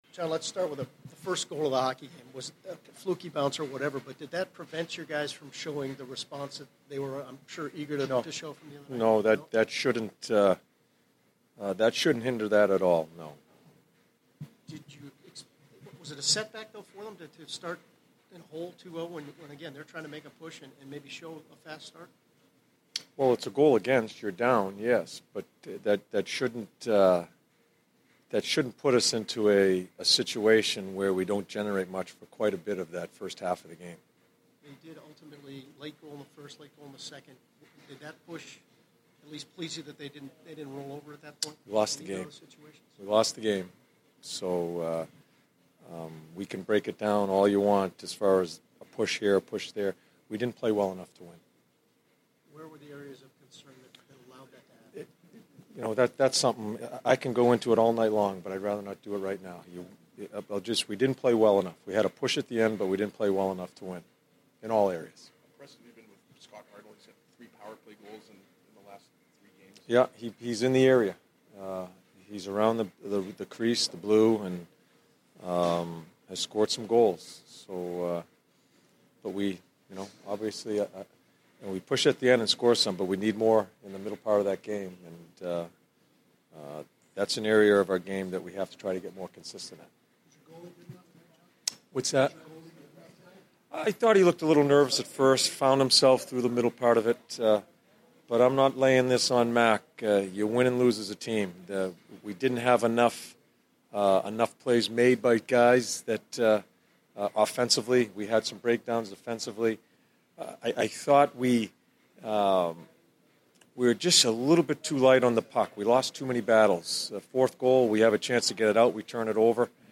John Tortorella Post-Game 12/10/15